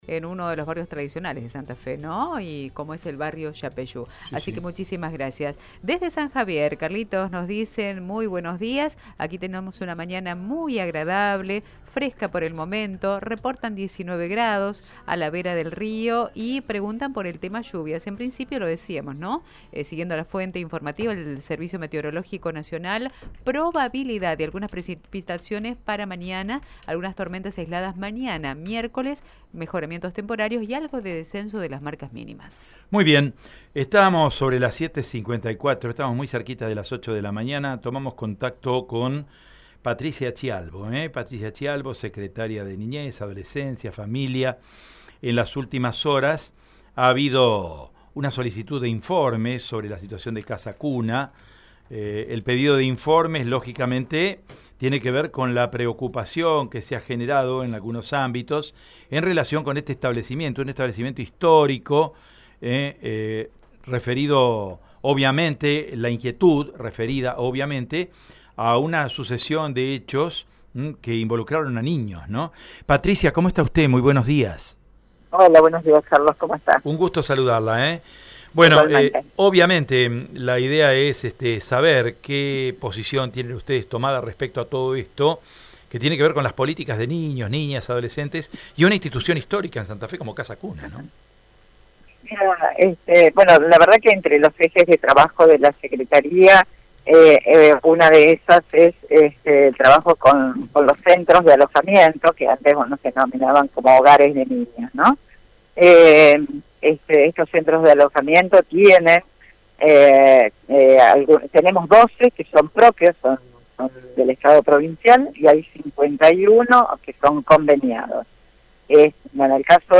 Patricia Chialvo en Radio EME: